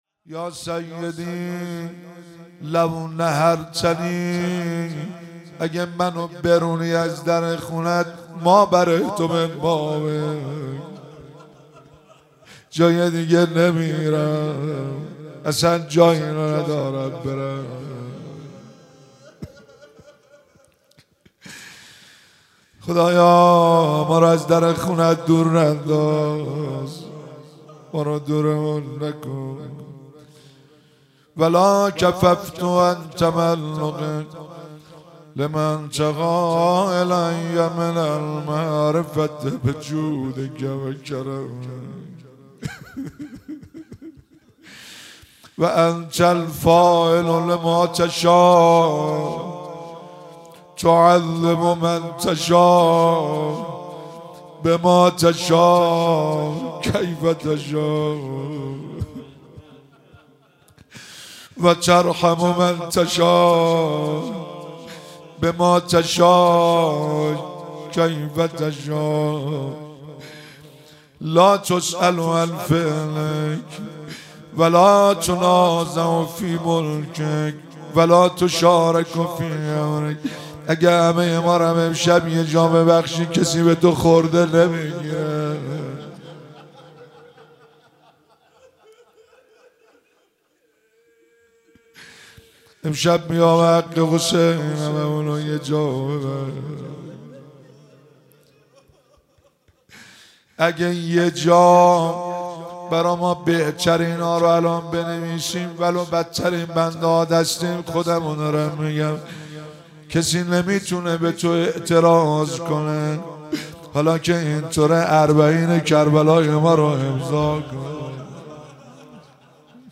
مراسم مناجات خوانی شب هفتم ماه رمضان 1444